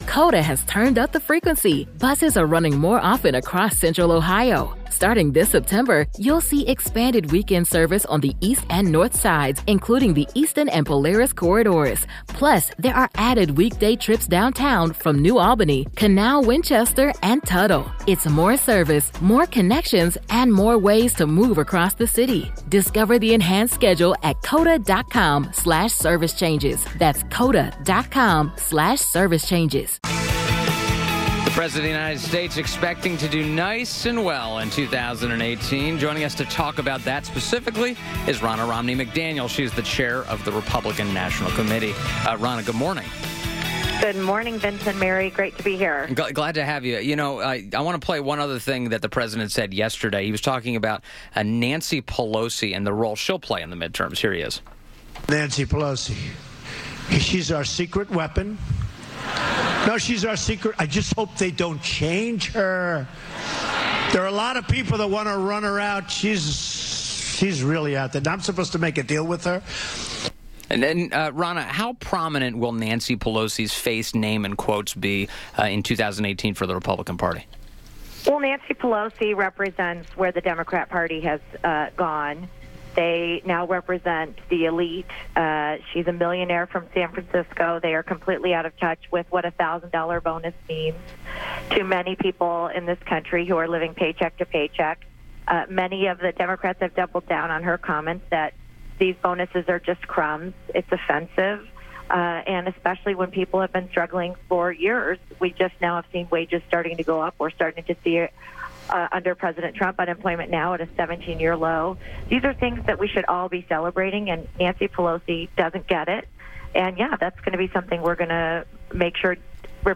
WMAL Interview - RNC CHAIR RONNA ROMNEY MCDANIEL - 02.06.18